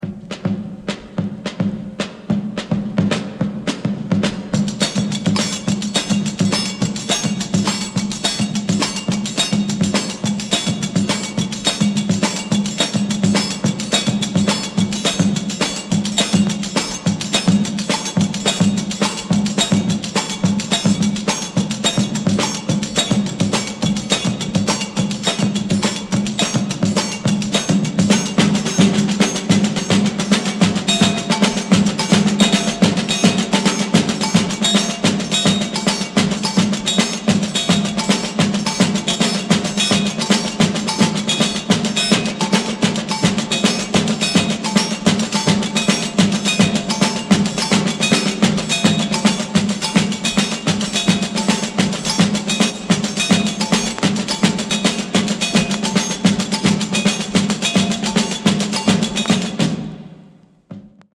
Noted soundtrack